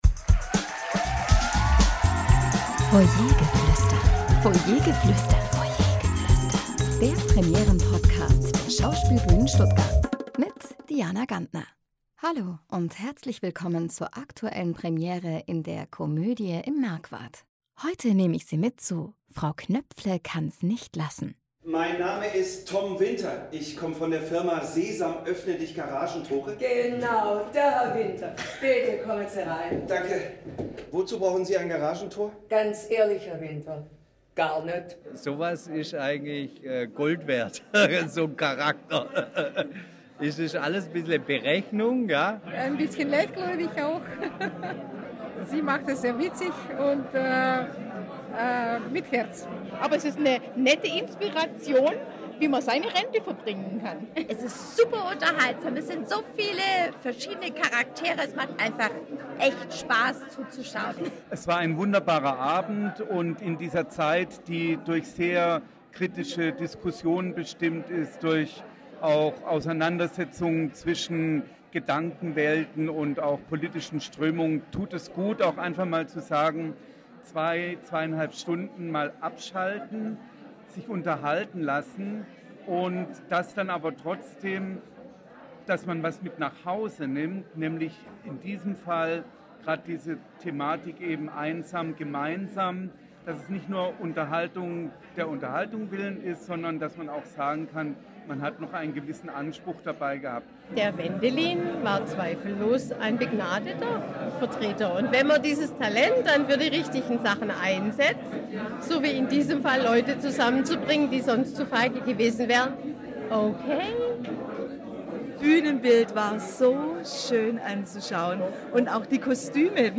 Zuschauerstimmen zur Premiere von “Frau Knöpfle kann´s nicht